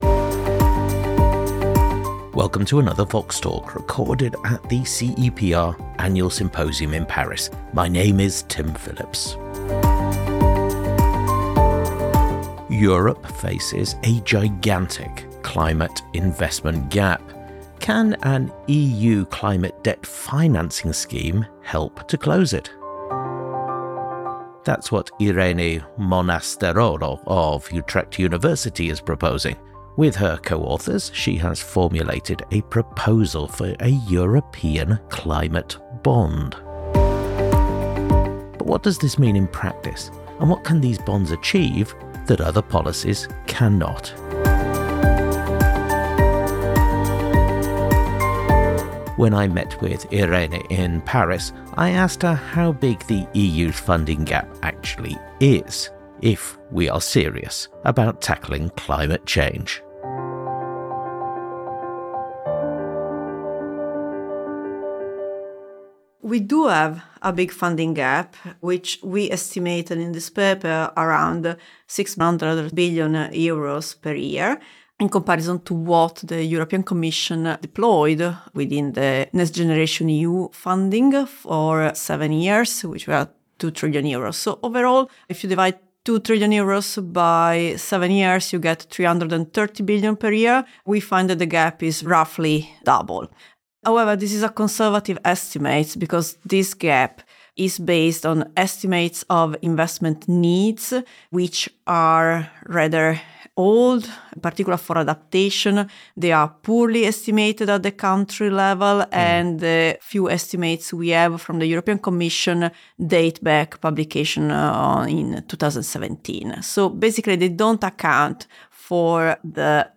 Recorded at the CEPR Paris Symposium. Europe faces a gigantic climate investment gap. Can an EU climate debt financing scheme help to close it?